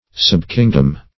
Subkingdom \Sub*king"dom\, n.